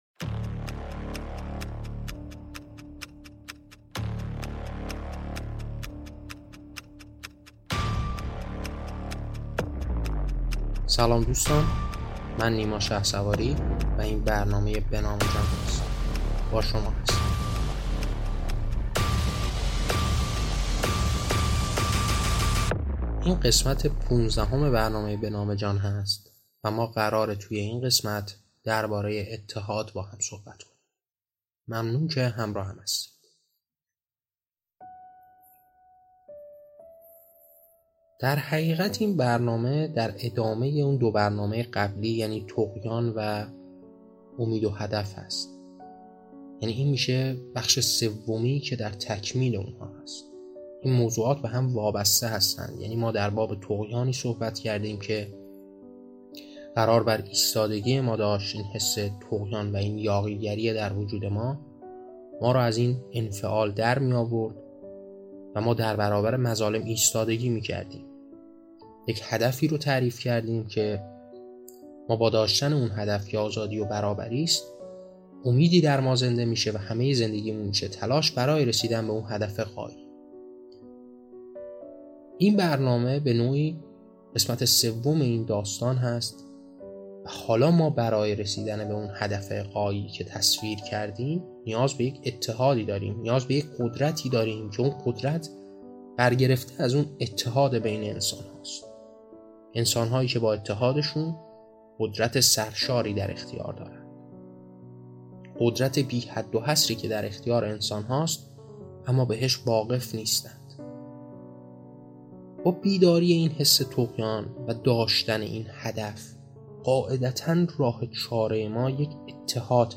گفتار بداهه